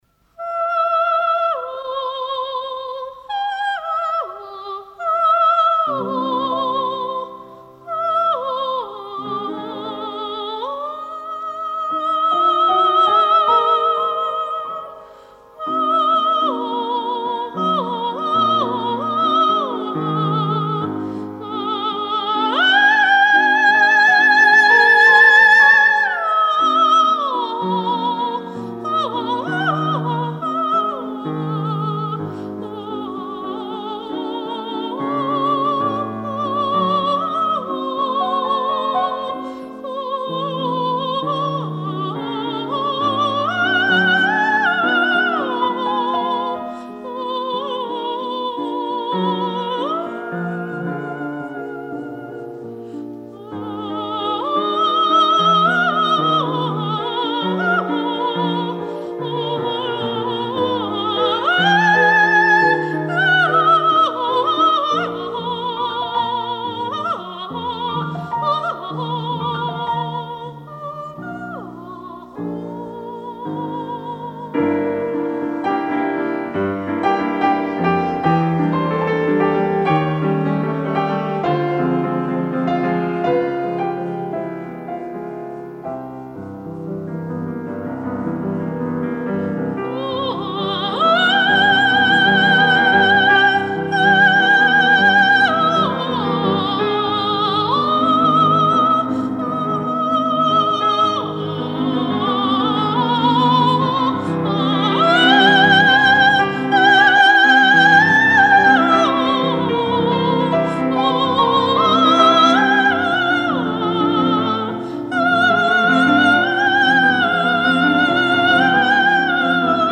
ВОКАЛИЗ – это произведение, написанное для  голоса без слов.